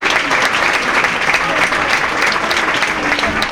Aplausos de gente en un concierto en un pub 3